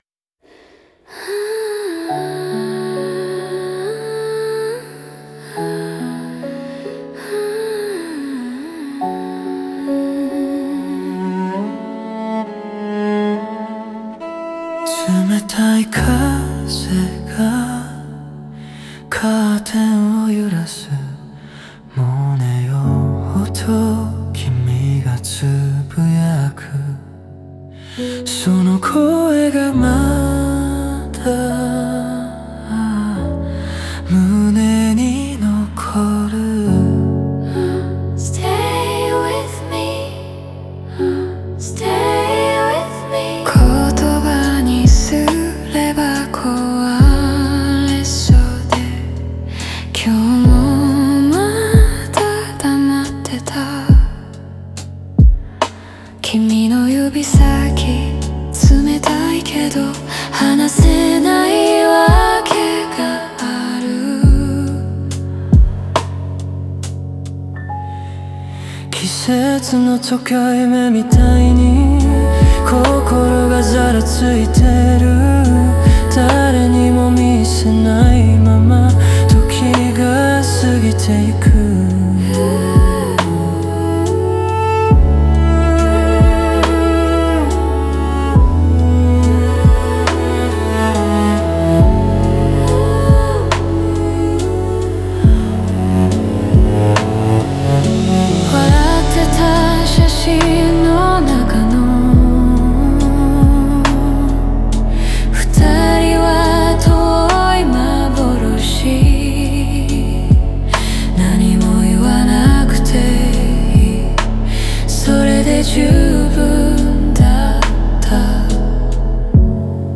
オリジナル曲♪
静寂の中に潜む温もりと哀愁が、聴く者の胸に柔らかく響く構造になっています。